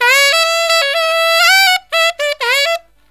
63SAXMD 11-L.wav